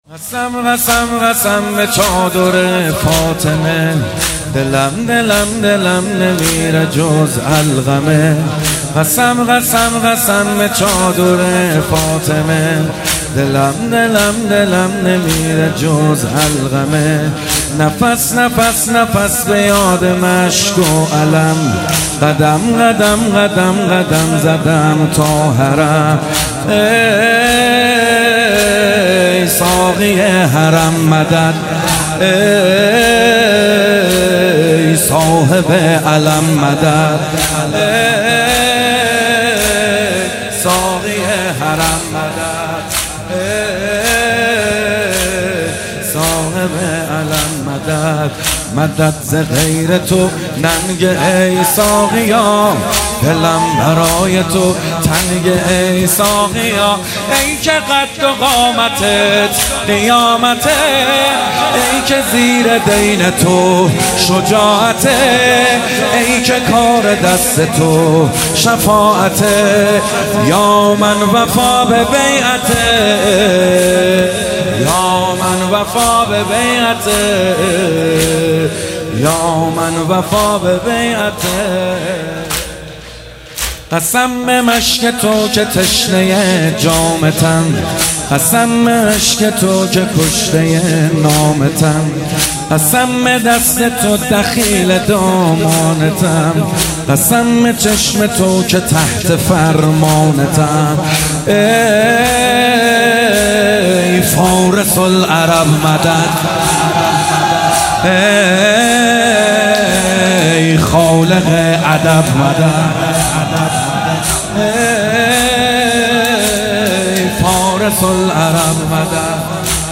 دلم نمیره جز علقمه محمدحسین حدادیان | وفات حضرت ام البنین (س) | پلان 3